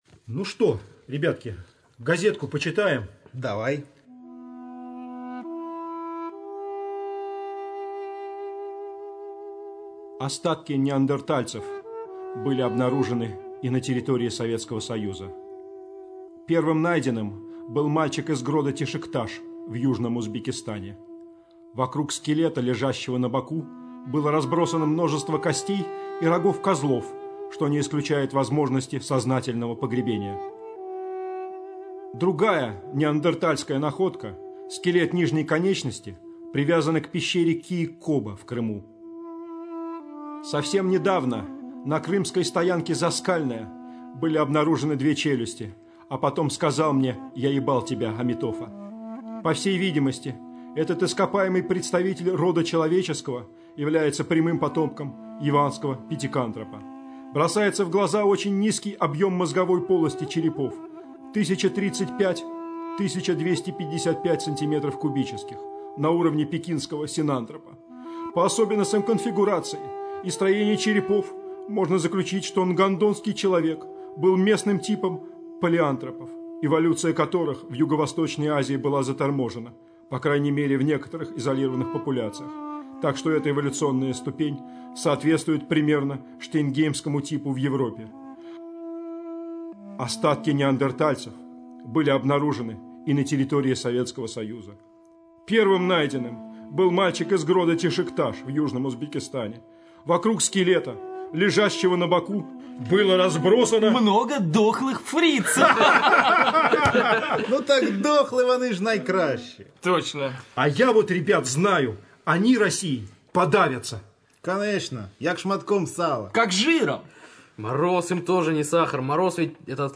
Радиопостановка пьесы Владимира Сорокина «Землянка», сделанная во время гастролей любительской театральной труппы журнала Game.EXE в кемеровском ДК «Металлург» в 2003 году.